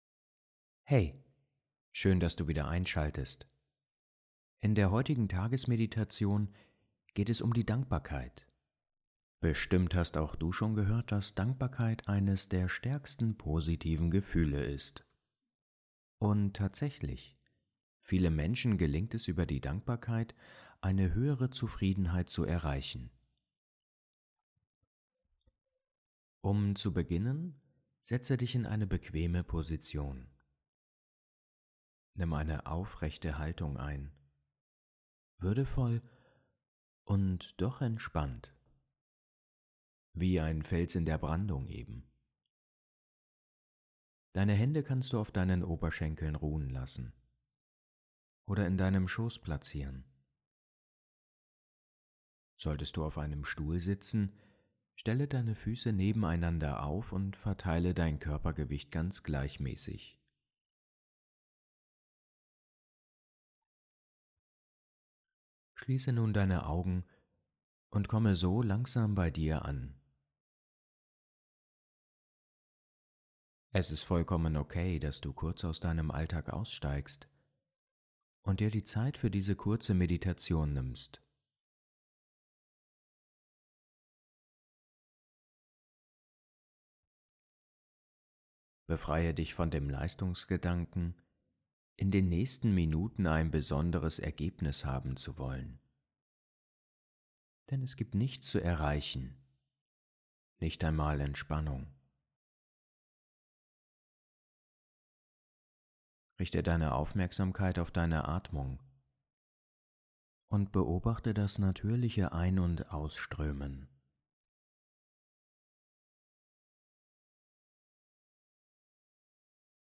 Eine Tagesmeditation, um Dankbarkeit bewusst zu erleben